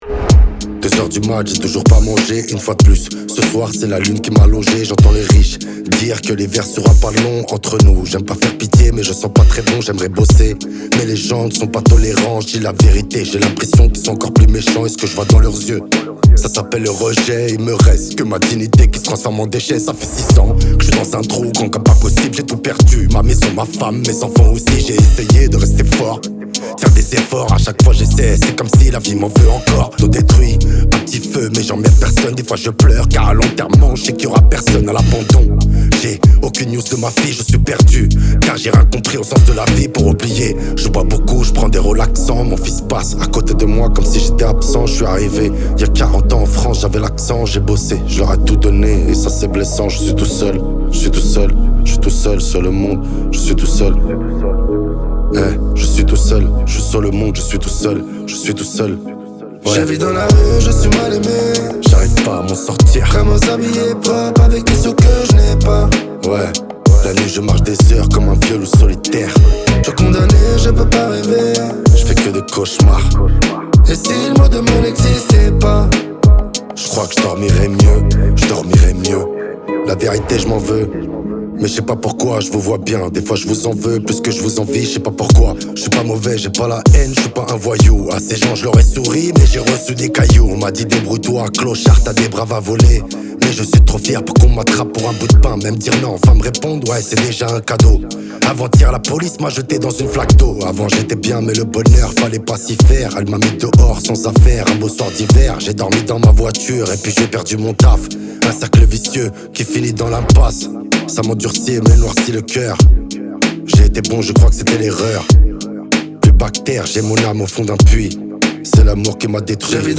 Rap Français